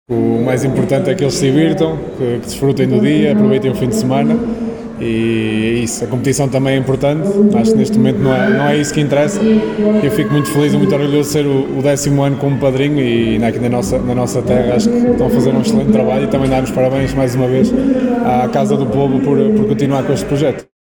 Claúdio Ramos, guarda-redes do Futebol Clube do Porto, padrinho deste Torneio desde a sua primeira edição, em declarações à Alive FM, disse que a Casa do Povo de Vila Nova de Paiva está de parabéns por dar continuidade a este projeto.